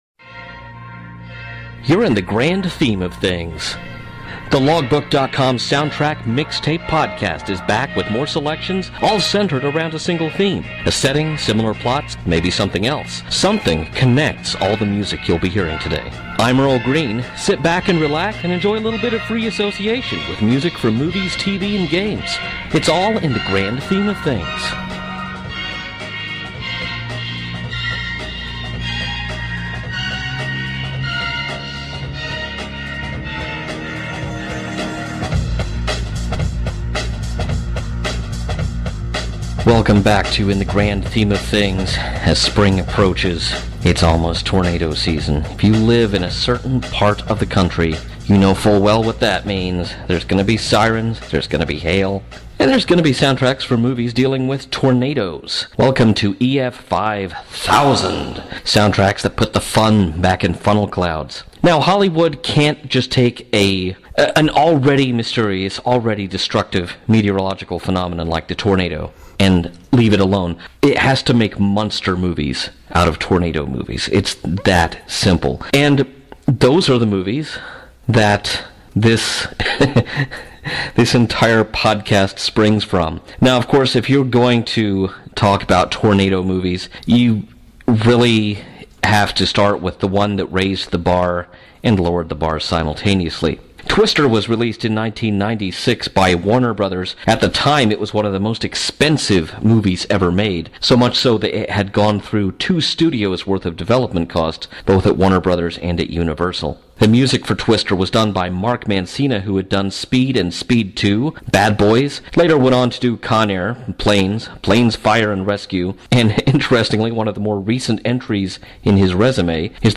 In The Grand Theme Of Things is back from its long slumber to put the “fun” back in “funnel clouds” with music from movies about tornadoes.